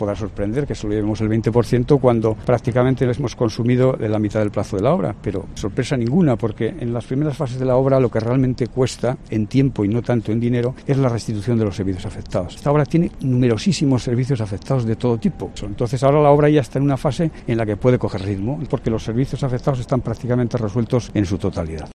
Enrique García Garrido, jefe de la Demarcación de Carreteras del Estado